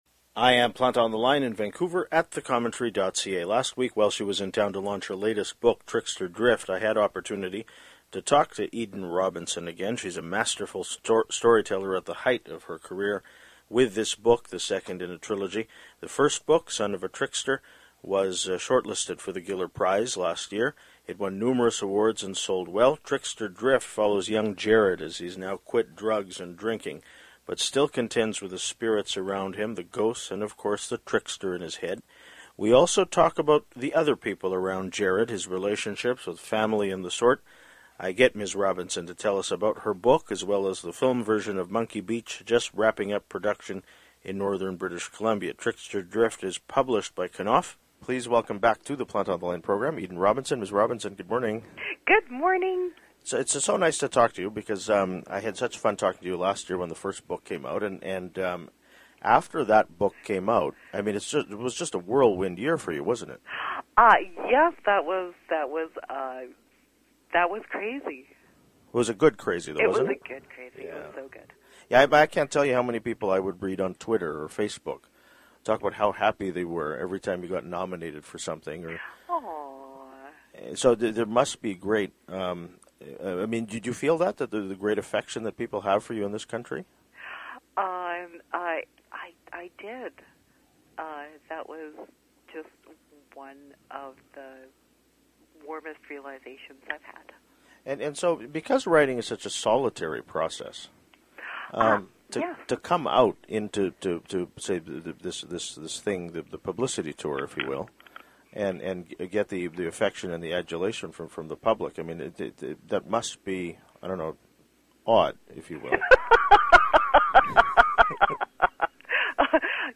Last week, while she was in town to launch her latest book, Trickster Drift , I had opportunity to talk to Eden Robinson again.